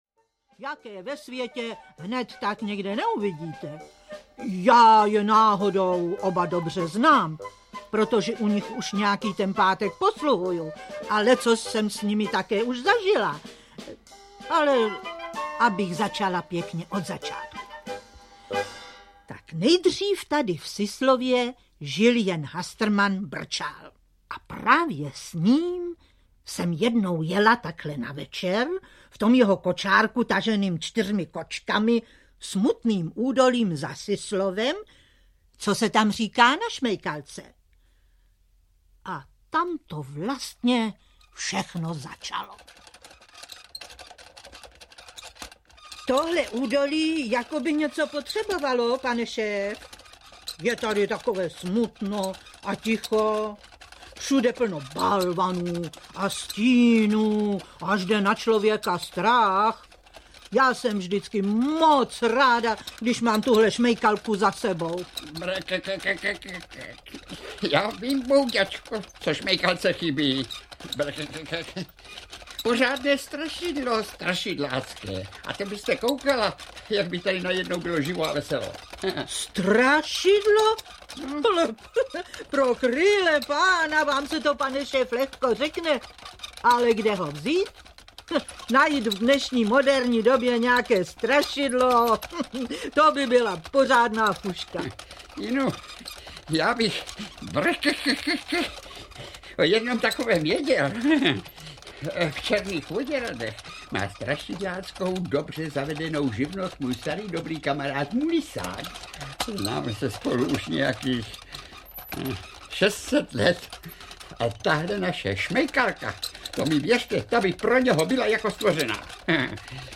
Pohádky a vyprávění slavného malíře audiokniha
Ukázka z knihy
• InterpretNaďa Gajerová, Marek Eben, František Smolík, Naďa Konvalinková, Lubomír Lipský, Jaroslav Kepka, Antonín Jedlička, Jiřina Jirásková, Alena Vránová, Regina Rázlová, Svatopluk Beneš, Jiřina Štěpničková, Václav Postránecký, Čestmír ml. Řanda, Vladimír Brabec,